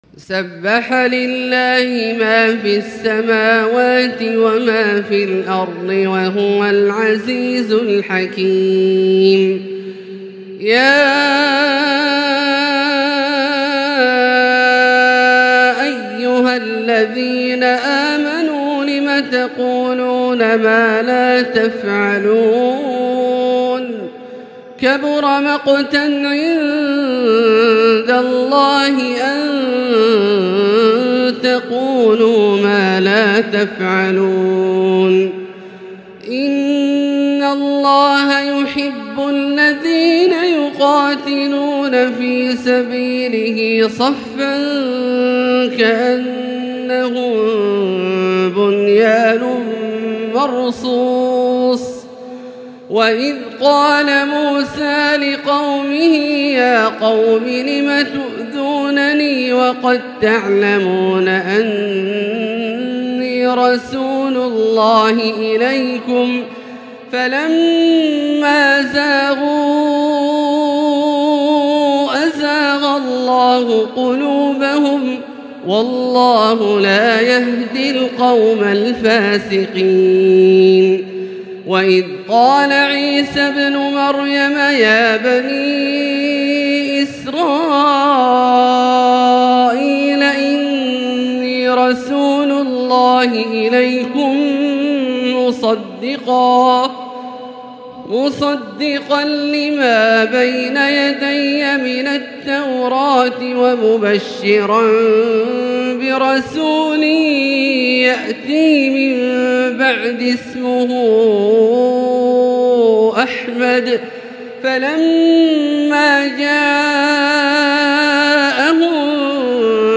تلاوة ملئت بالجمال لـ سورة الصف كاملة للشيخ د. عبدالله الجهني من المسجد الحرام | Surat As-Saff > تصوير مرئي للسور الكاملة من المسجد الحرام 🕋 > المزيد - تلاوات عبدالله الجهني